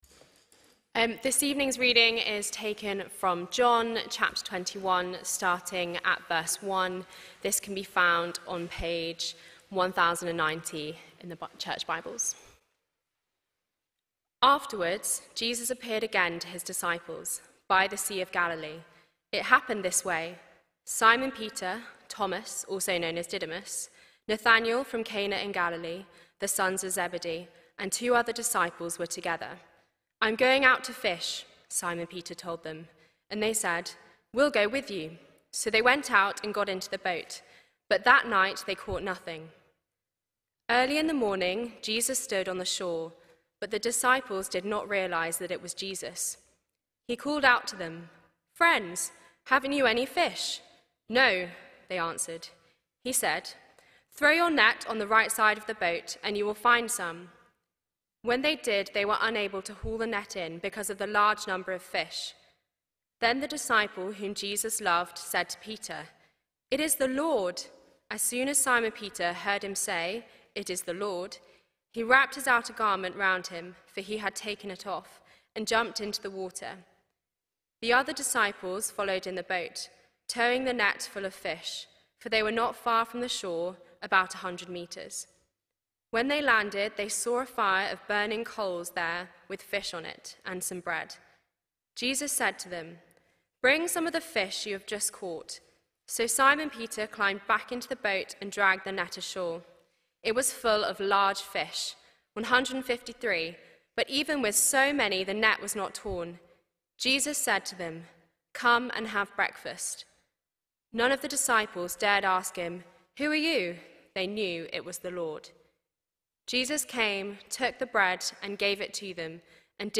John 21:1-14 – 12th April 2026 – PM Service